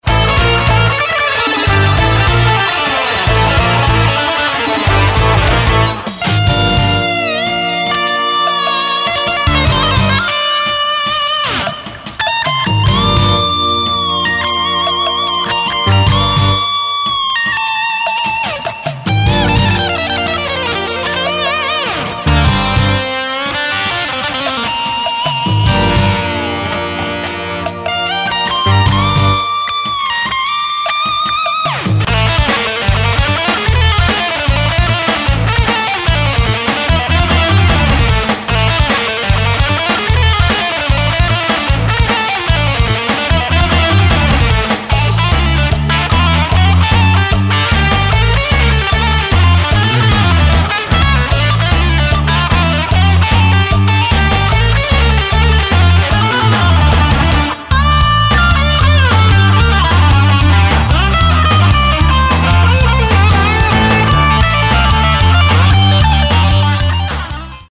10 instrumentala